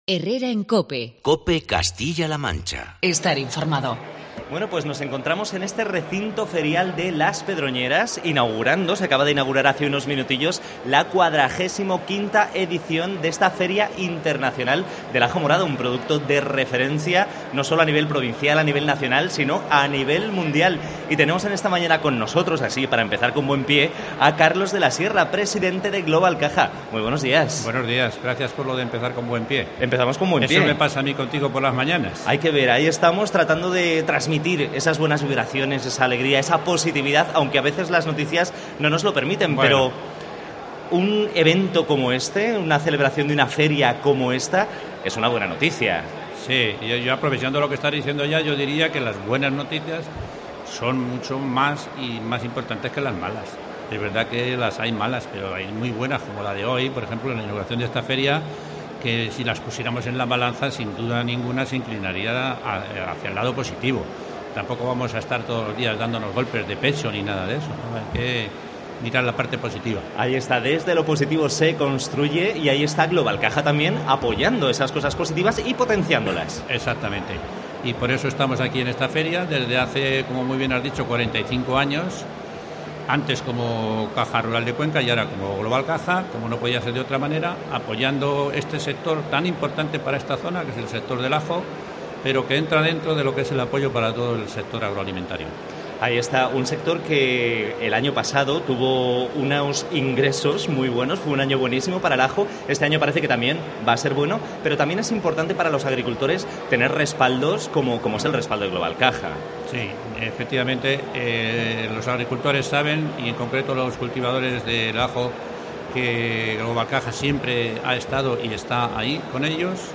COPE Castilla-La Mancha se ha trasladado a la XLV edición de la Feria Internacional del Ajo de las Pedroñeras para acercar a todos los castellanos manchegos las propiedades de este producto tan nuestro, desde la denominada "Capital Mundial del Ajo", desde las Pedroñeras (Cuenca).